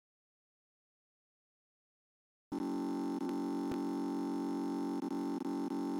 Play Blue Screen Sound Effect - SoundBoardGuy
blue-screen-sound-effect.mp3